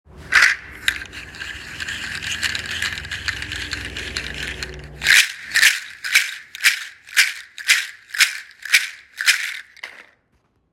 • large seed shaker originally from Togo
• loud hollow clacking sound
30 seed wood handle audio sample
Toga-wood-handle-30-seed.m4a